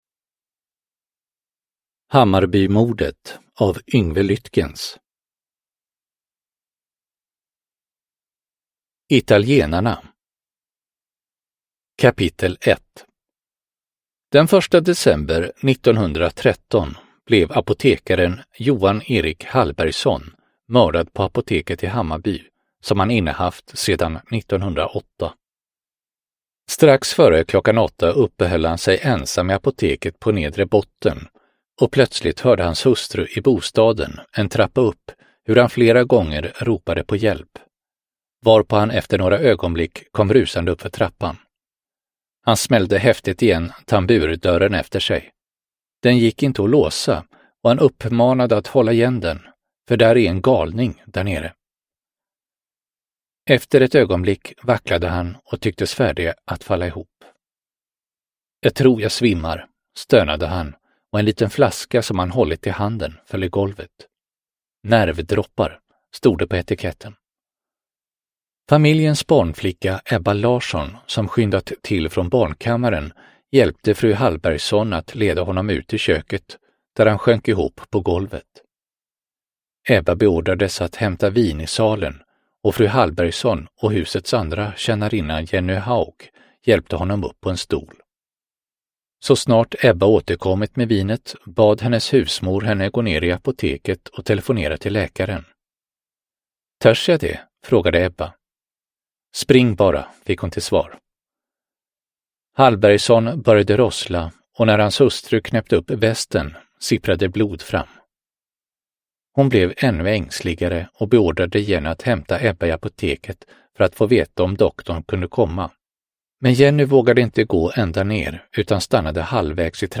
Hammarbymordet : Historiska mord del 5 – Ljudbok – Laddas ner